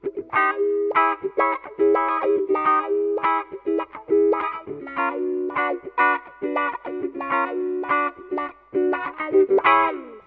Mithilfe eines Gitarrenverstärkers kann diese Spannung hörbar gemacht werden (Hörbeispiel:
Clean_wah.ogg